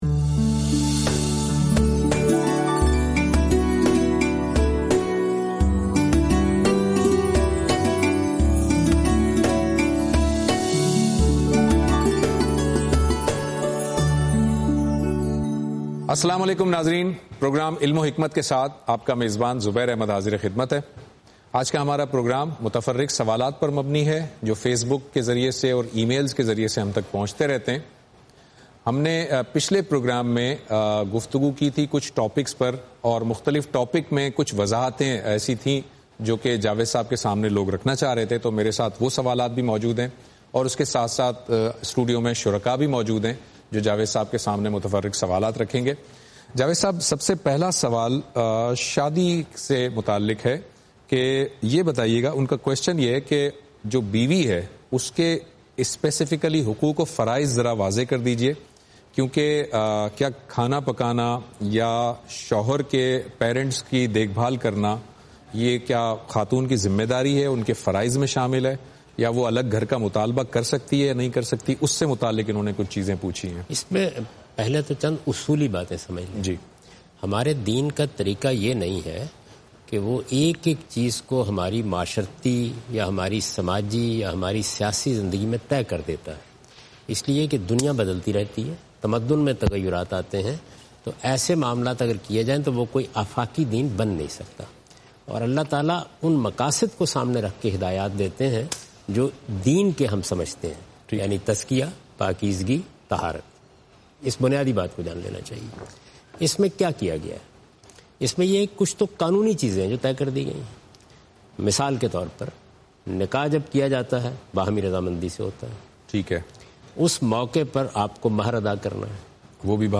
In this program Javed Ahmad Ghamidi answers miscellaneous questions.